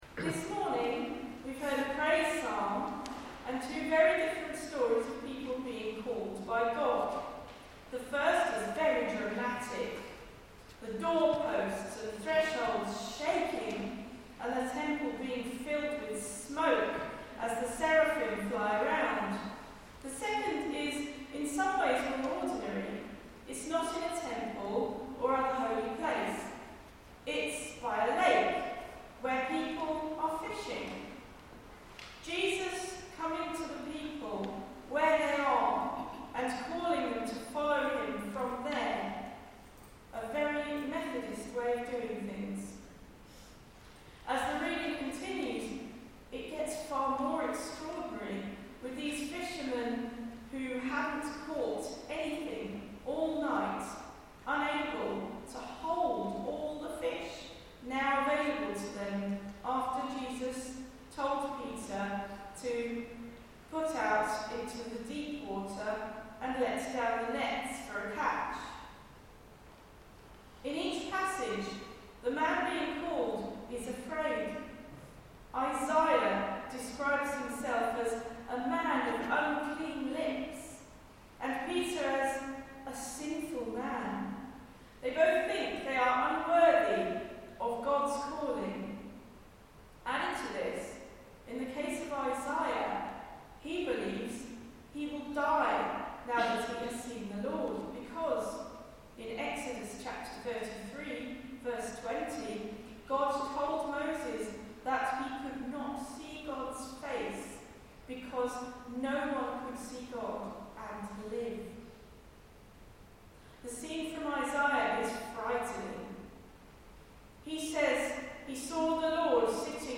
Sermon preached on 9 Feb 2025 about being called to follow God and Jesus, however unprepared we may feel, and what it means to "catch people", instead of fish. Readings were Psalm 138, Isaiah 6: 1-8 and Luke 5: 1-11